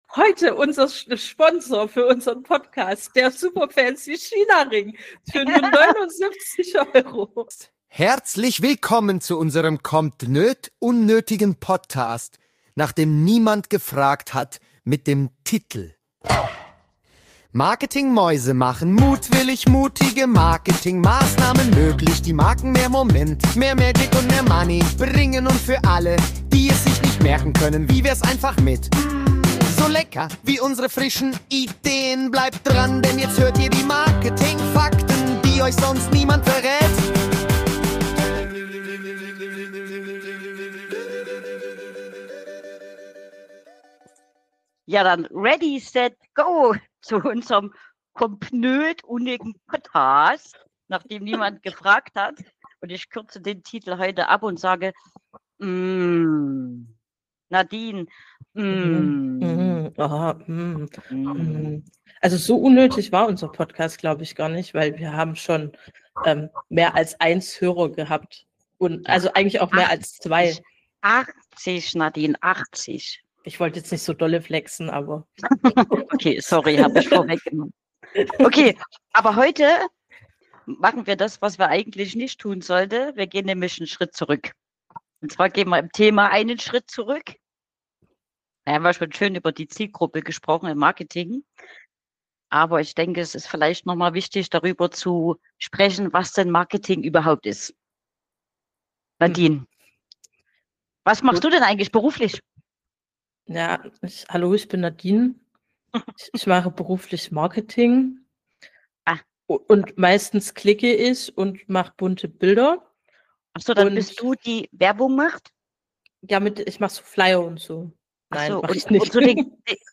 Diese Folge ist ein leicht chaotischer, stellenweise wütender, aber sehr ehrlicher Grundlagen-Rant über Marketing.